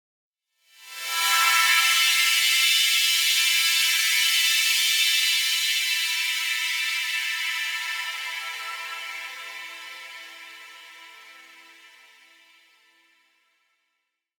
SaS_HiFilterPad02-A.wav